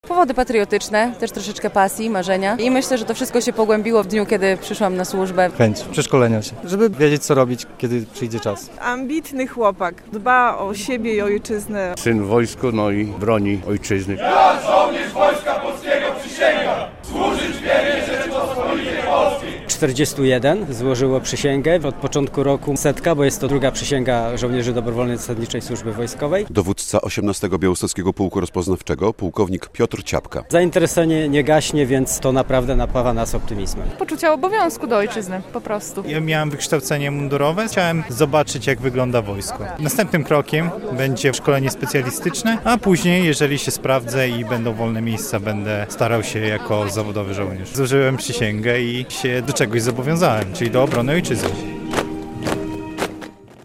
40 żołnierzy dobrowolnej zasadniczej służby wojskowej złożyło w Białymstoku uroczystą przysięgę - relacja